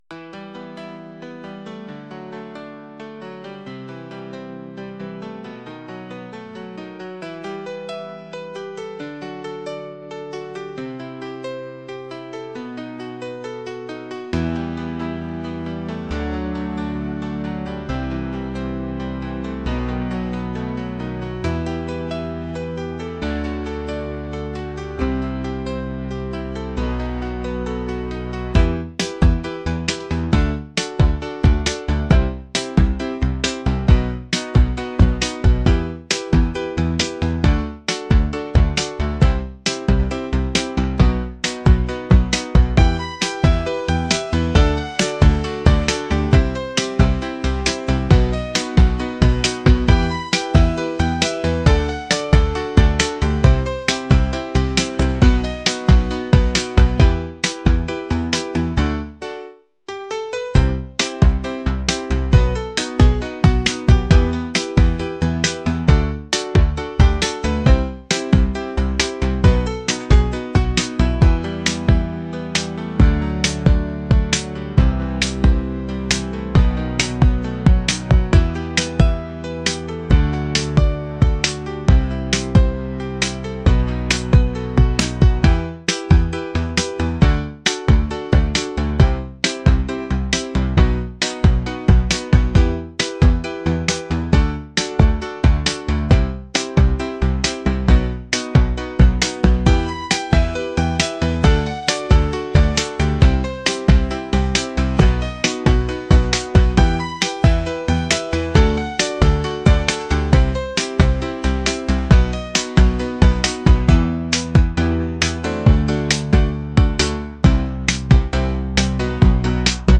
pop | ambient | lofi & chill beats